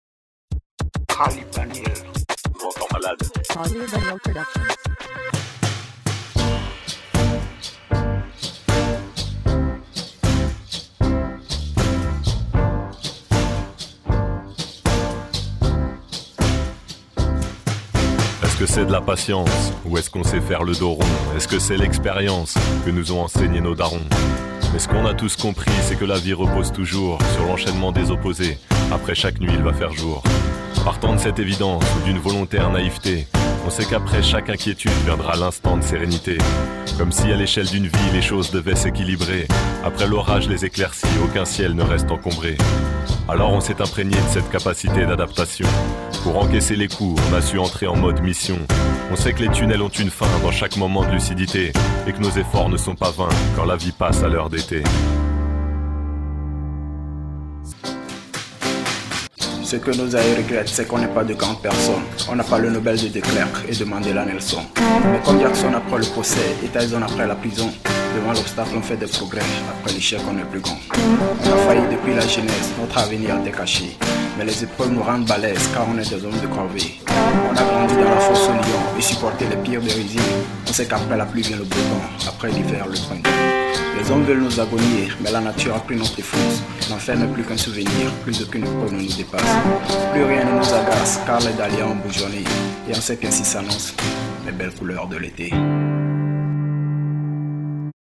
Beat originel modifié et utilisé